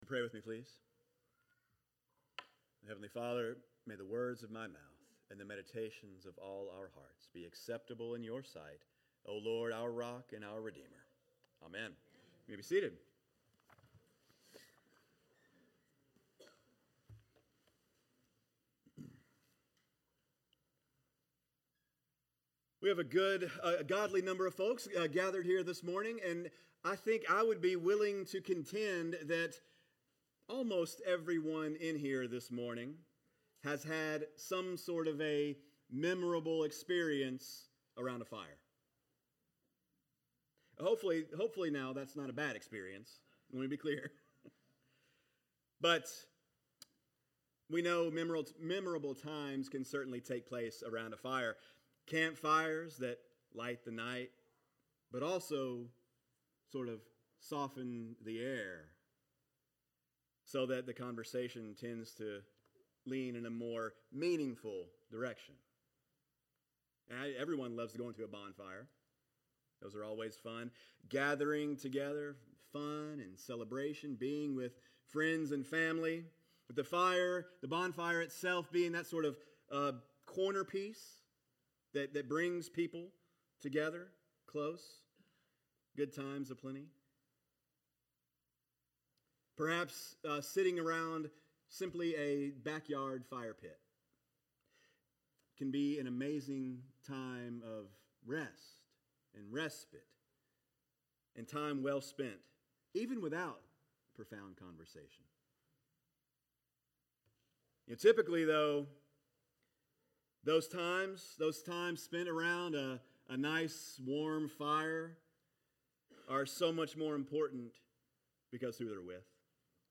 Sermons | St. John's Parish Church